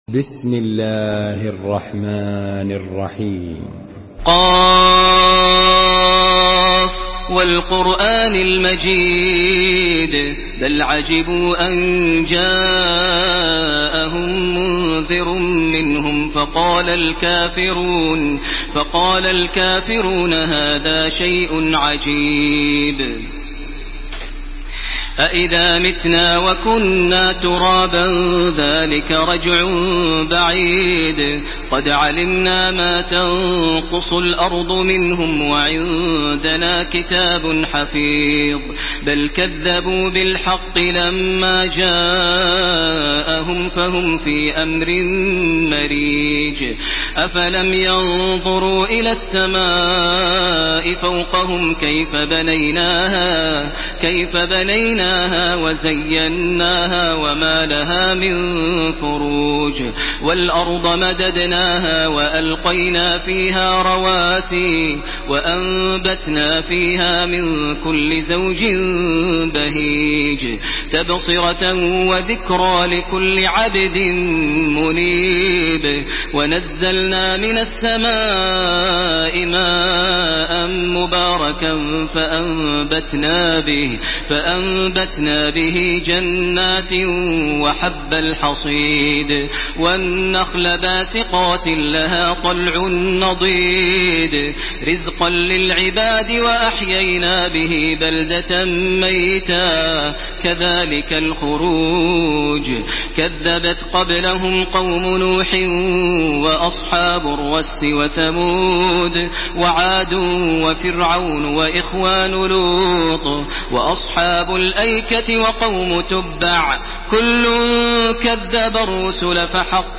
تحميل سورة ق بصوت تراويح الحرم المكي 1431
مرتل